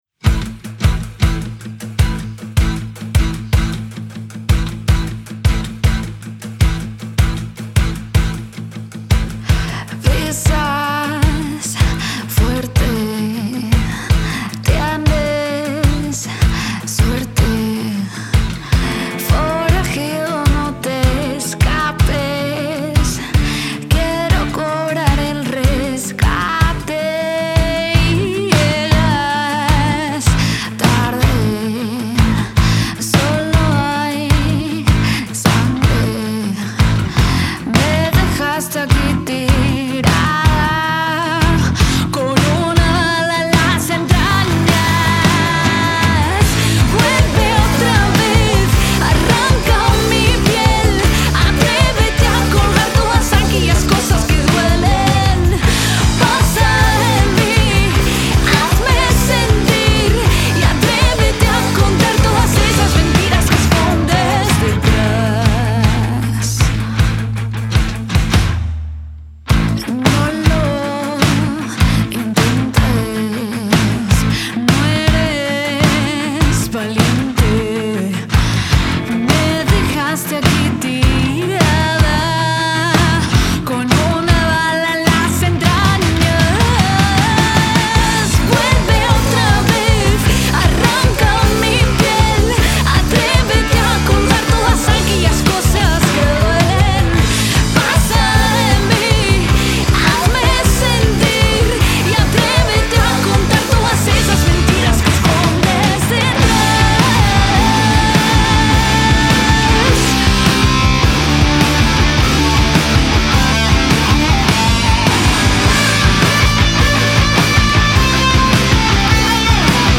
La banda rockera
voz y guitarra
guitarra líder
bajo
batería